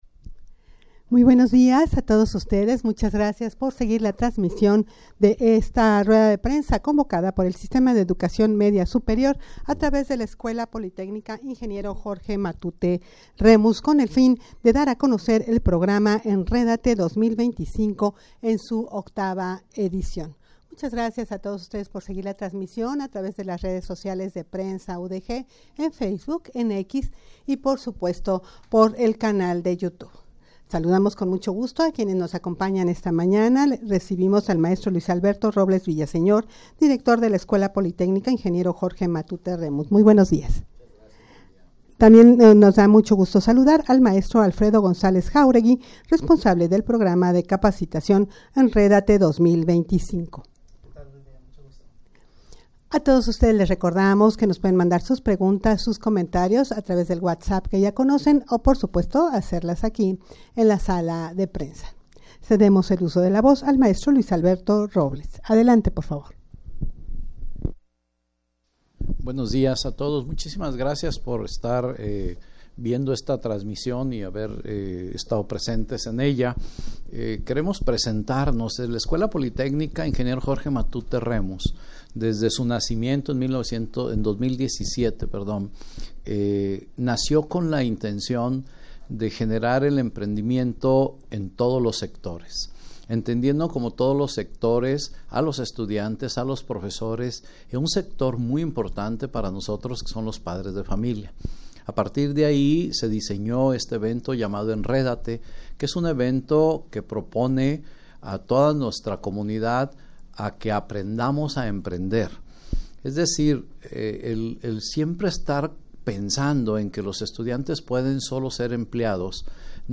rueda-de-prensa-para-dar-a-conocer-enredate-2025-octava-edicion.mp3